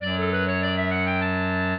clarinet
minuet11-4.wav